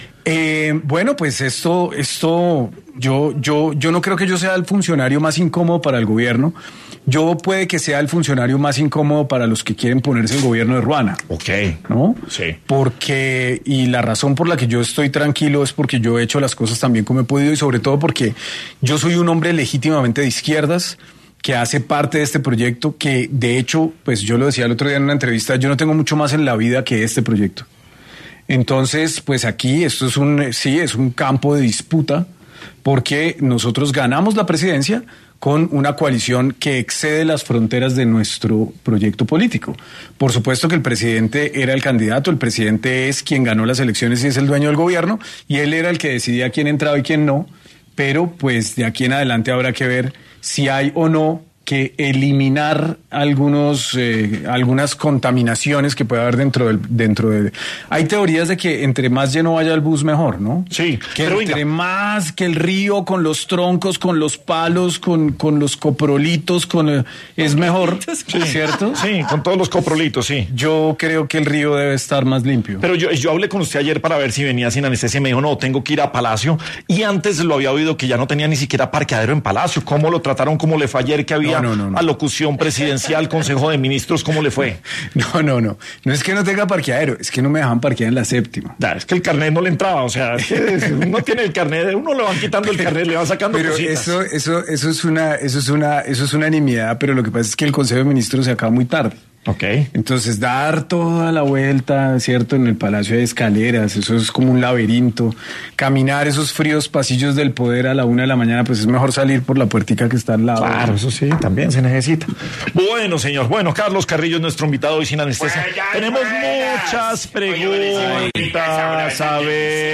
En Sin Anestesia de Caracol Radio, estuvo Carlos Carillo, director de la UNGRD para hablar sobre su gestión dentro de la entidad:
En medio de la entrevista Carrillo sostuvo que este proyecto es, prácticamente, su vida política y personal.